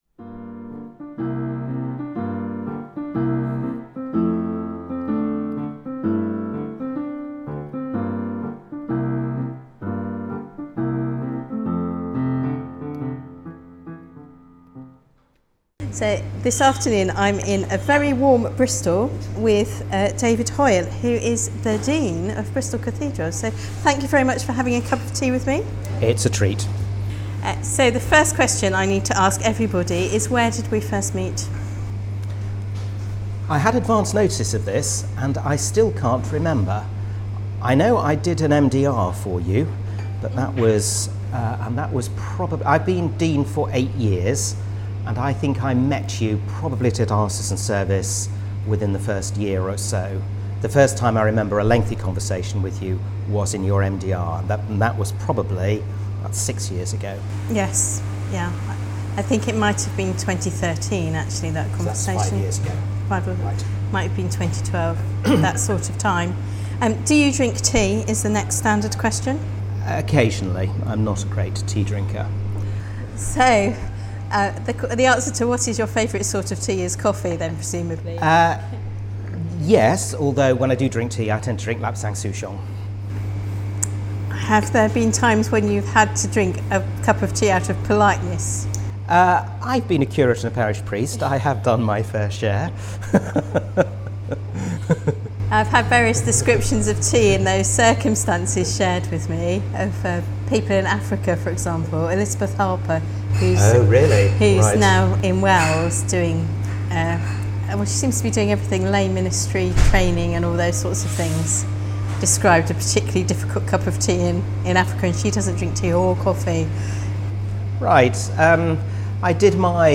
In a really noisy cathedral coffee shop